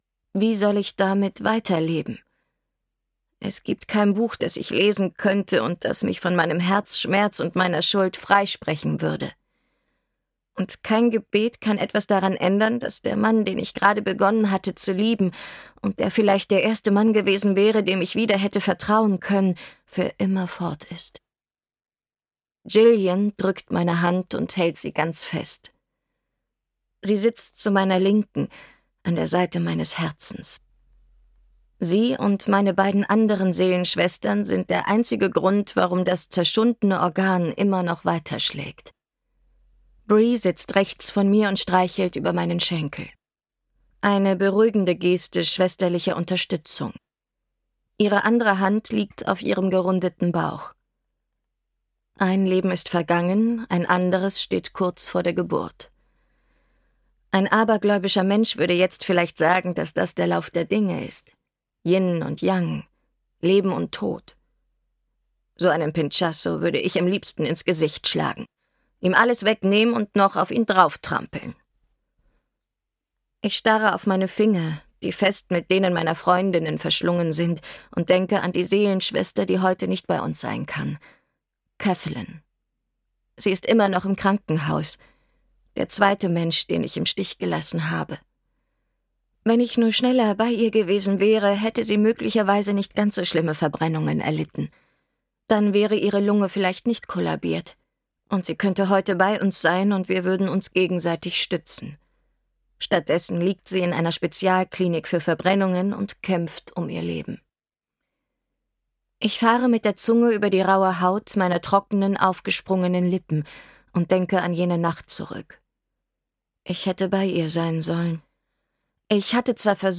Trinity - Bittersüße Träume (Die Trinity-Serie 4) - Audrey Carlan - Hörbuch